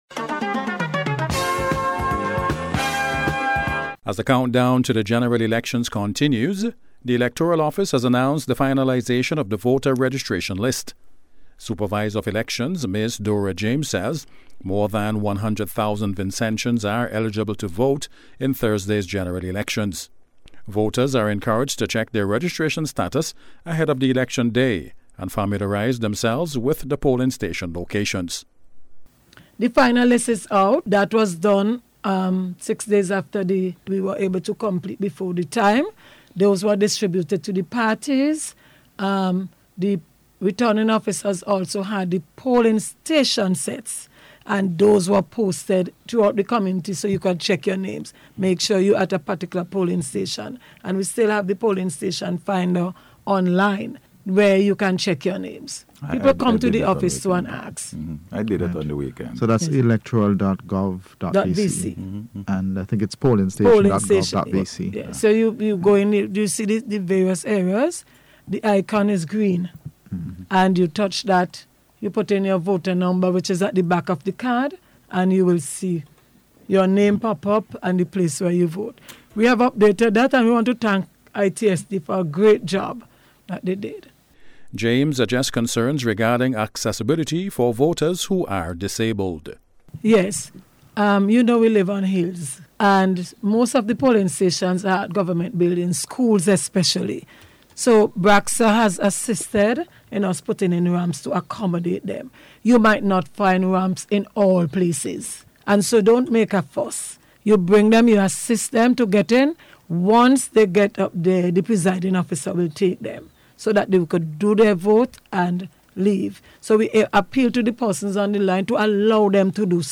ELECTION-COUNTDOWN-REPORT.mp3